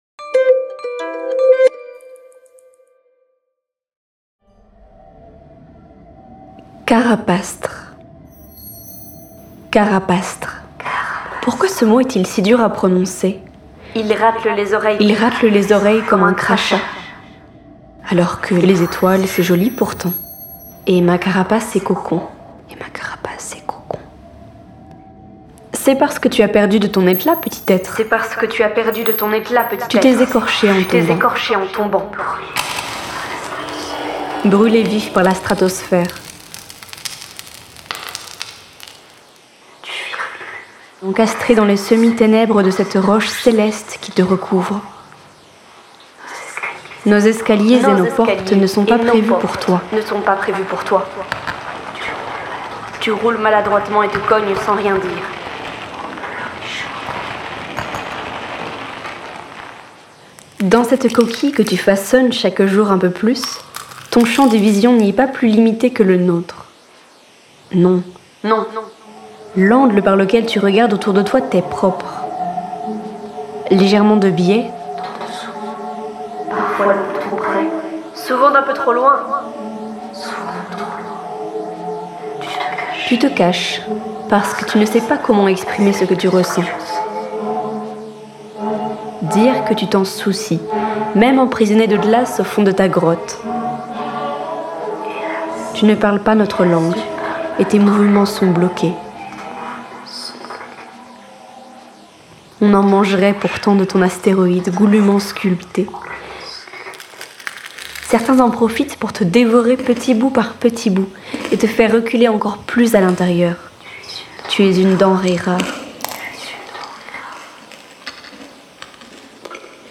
Poème
Musiques : libres de droit